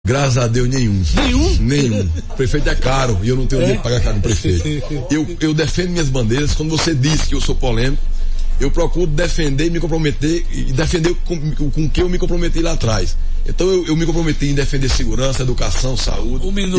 A declaração é do deputado estadual Wallber Virgolino (PL), durante entrevista ao programa Arapuan Verdade, do Sistema Arapuan de Comunicação nesta segunda-feira (6), ao revelar que não possui apoio de nenhum prefeito dos 223 municípios da Paraíba.